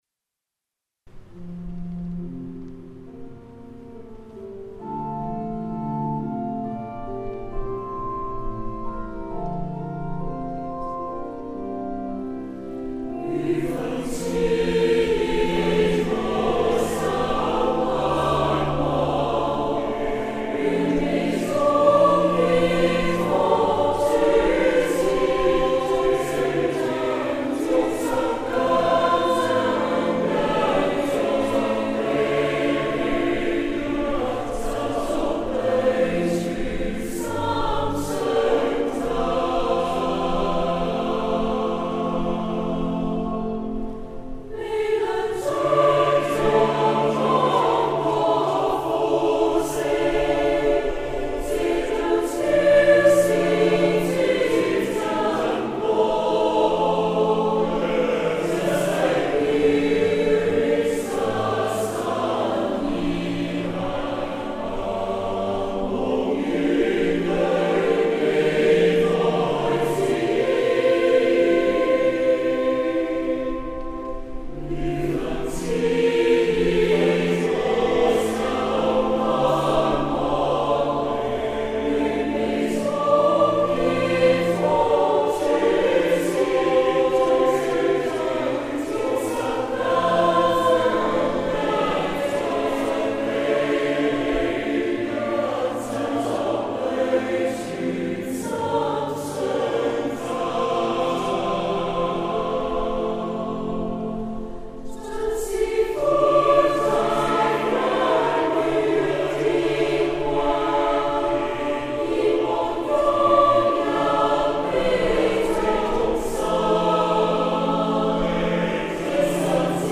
四聲部/粵語